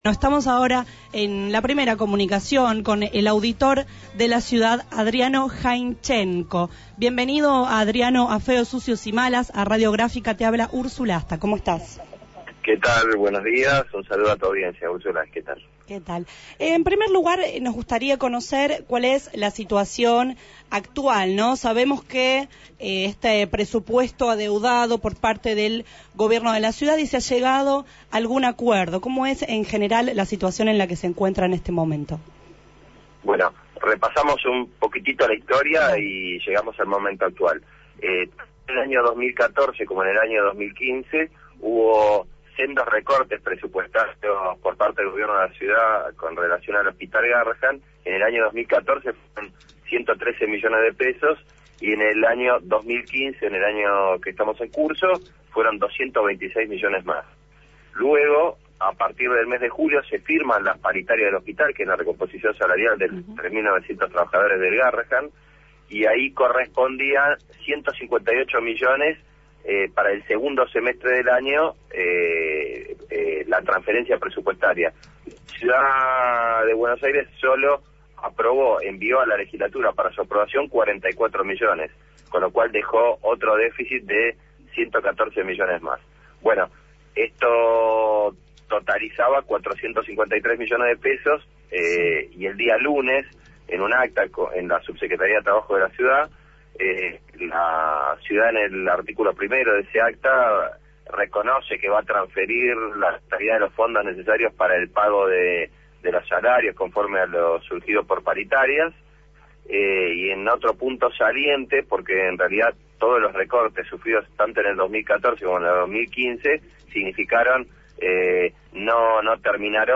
Sobre esto, el auditor general de la Ciudad Adriano Jaichenco habló en el programa Feos, Sucios y Malas.